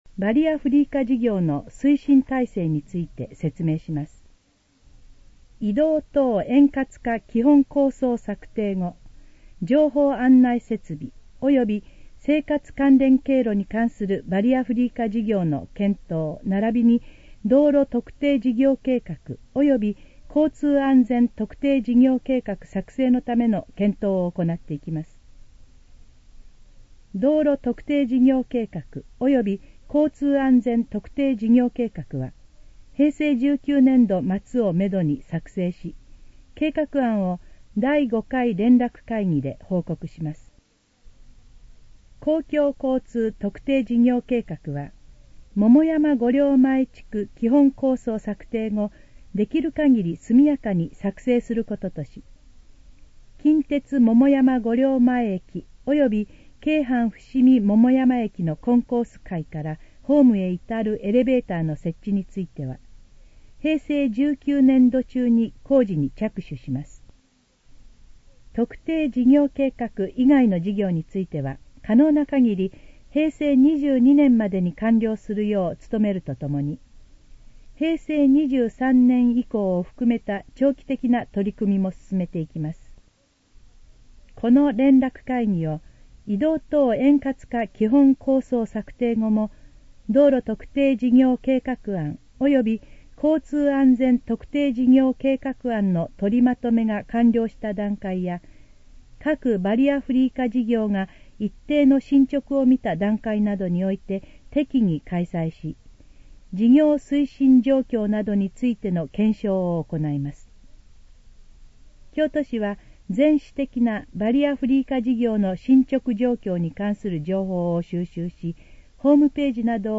このページの要約を音声で読み上げます。
ナレーション再生 約486KB